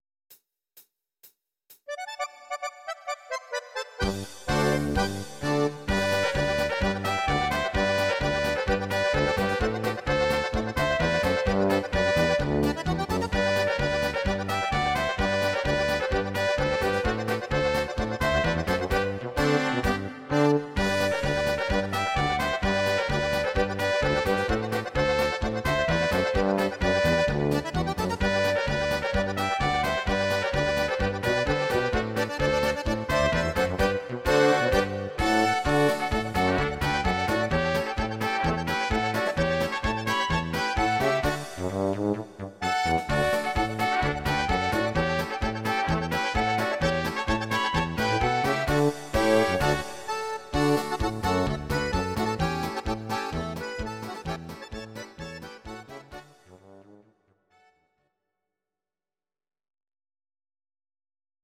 Please note: no vocals and no karaoke included.
instr. Orchester